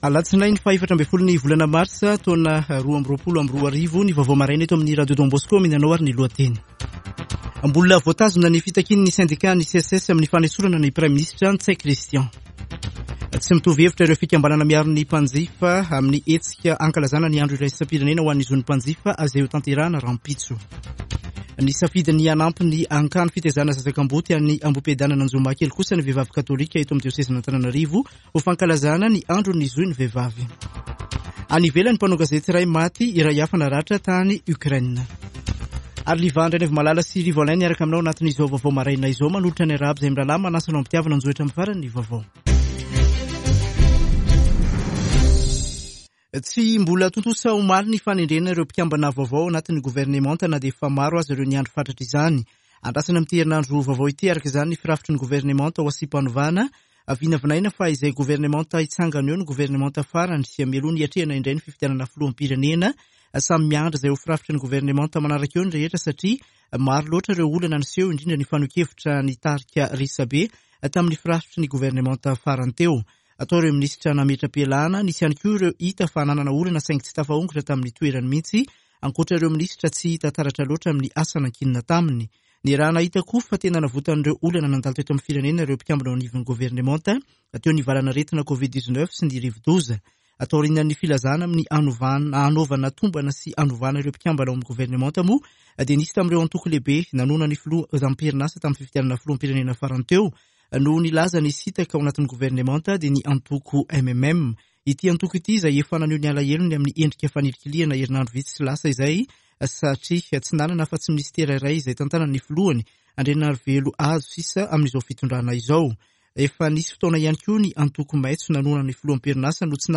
[Vaovao maraina] Alatsinainy 14 marsa 2022